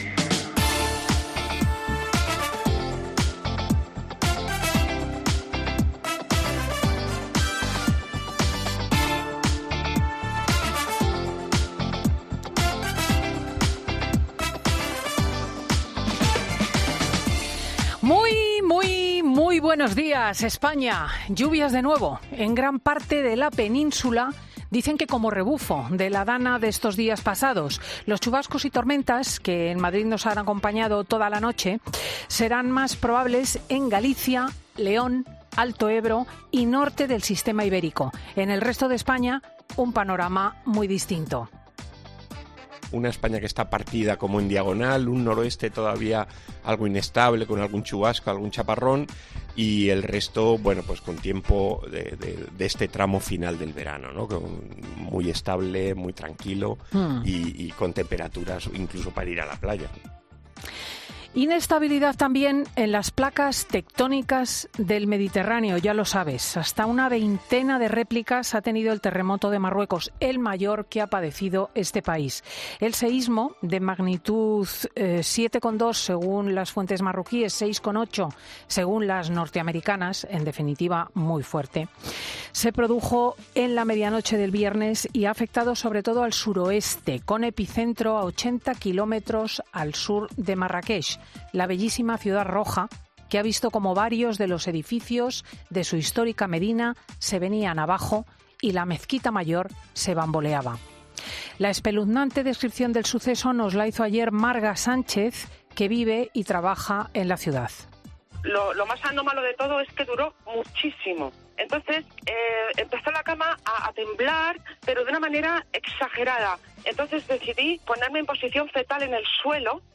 Escucha ya el monólogo de Cristina López Schlichting de este domingo 10 de septiembre de 2023